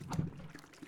water-tchwotchse
bath bubble burp click drain dribble dribbling drip sound effect free sound royalty free Nature